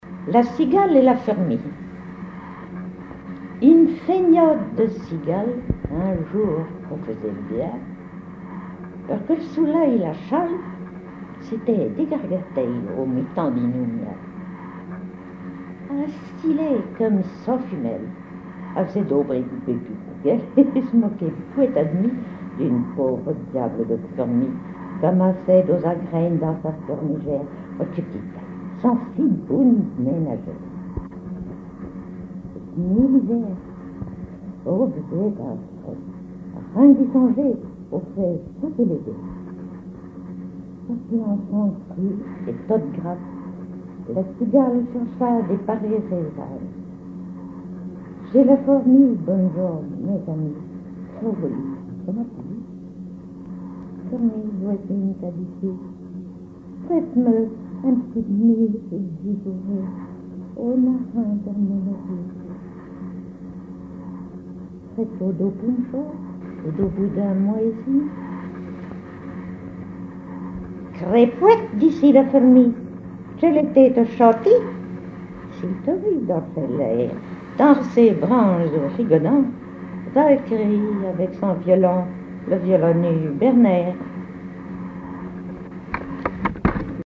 Langue Patois local
Genre fable
Catégorie Récit